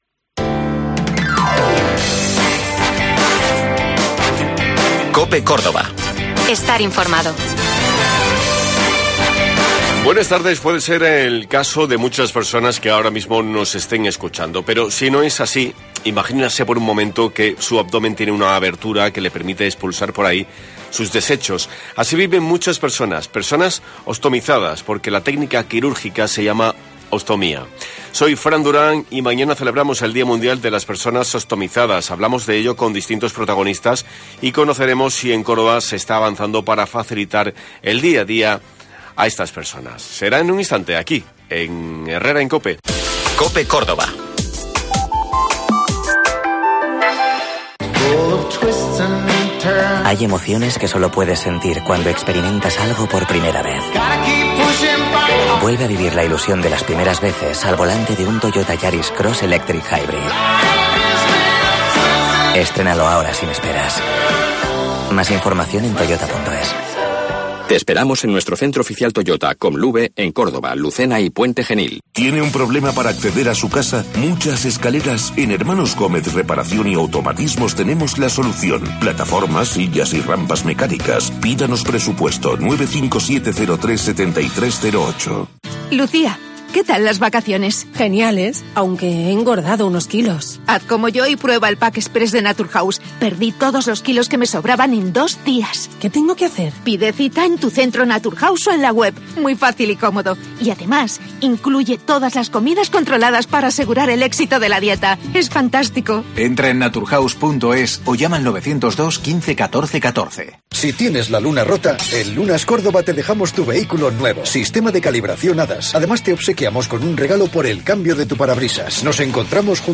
Escuchamos a distintos protagonistas con motivo del Día Mundial de las personas Otomizadas.